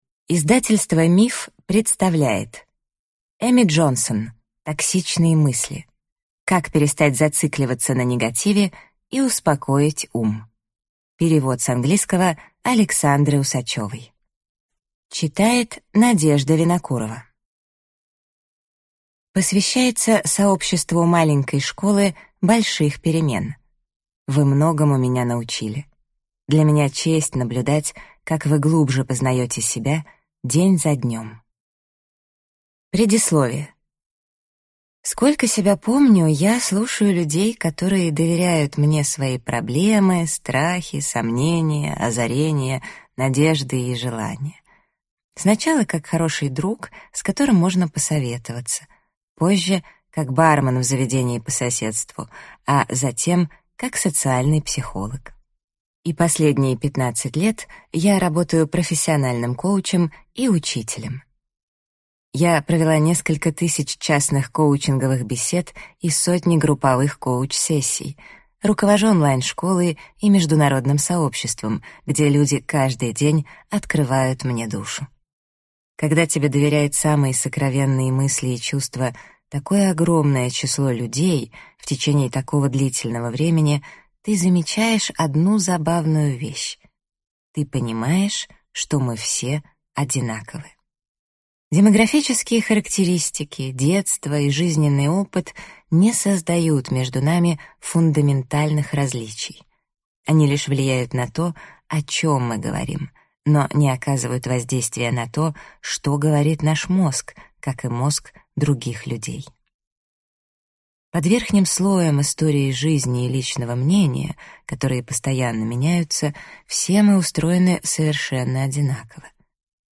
Аудиокнига Токсичные мысли. Как перестать зацикливаться на негативе и успокоить ум | Библиотека аудиокниг